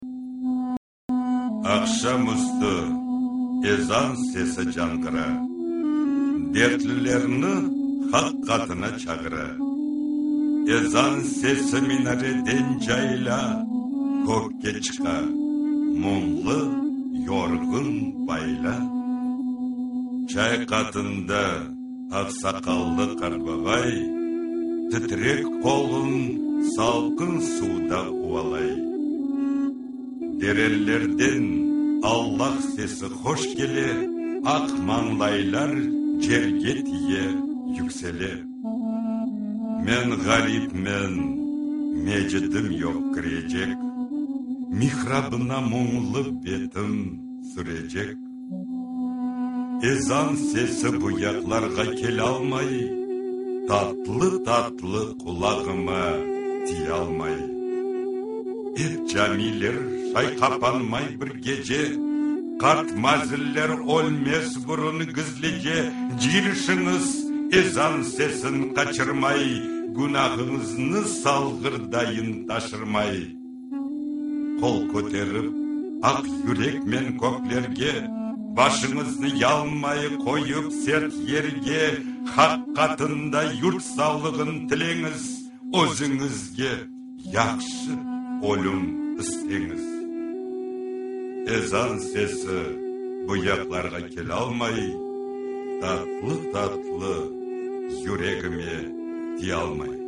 şiiri